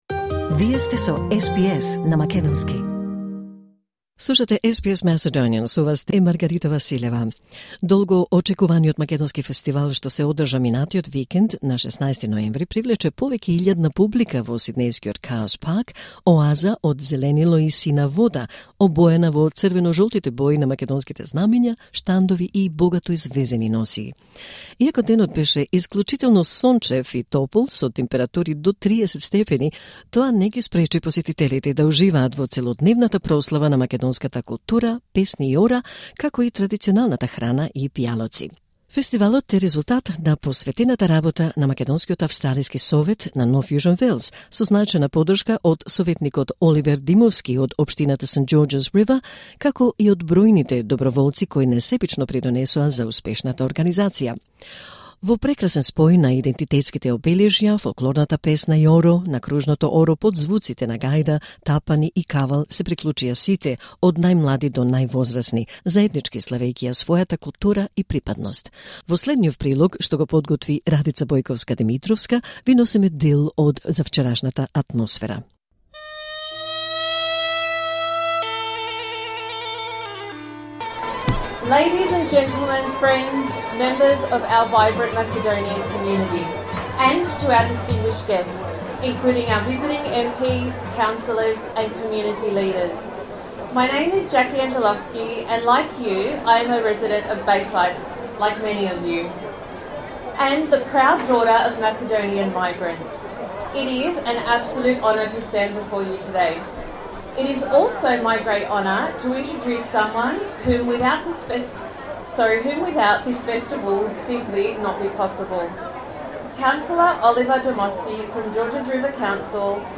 Во прекрасен спој на идентитетските обележја, фолклорната песна и оро, со звуците на гајда, тапани и кавал се приклучија сите на оро – од најмлади до највозрасни, заеднички славејќи ја својата култура и припадност.
Во следниов прилог ви носиме дел од прекрасната атмосфера полна со емоции, гордост и заедништво.